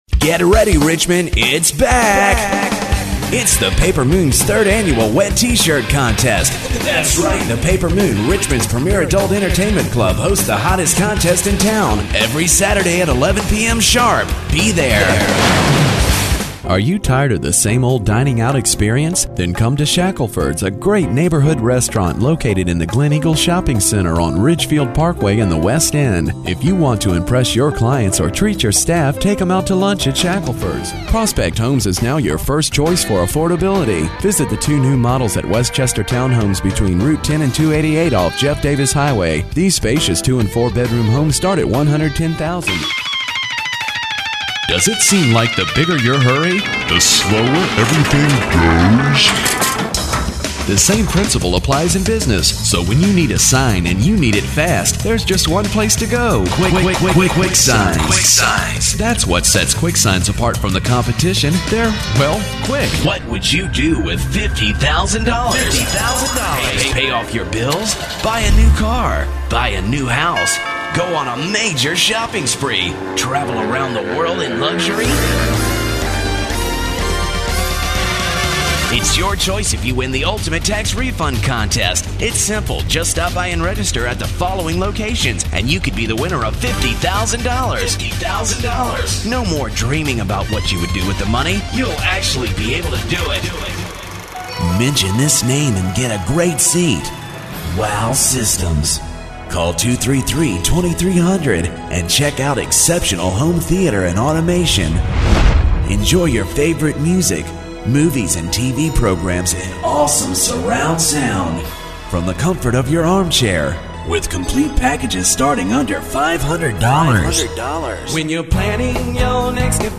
Here is a compilation of spots voiced by me during 2003.